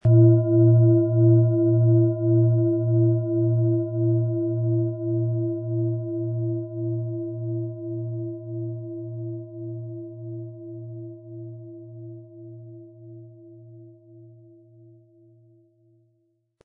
Planetenton 1
Von Hand getriebene Schale mit dem Planetenton Mond.
Wie klingt diese tibetische Klangschale mit dem Planetenton Mond?
SchalenformBihar
MaterialBronze